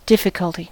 difficulty: Wikimedia Commons US English Pronunciations
En-us-difficulty.WAV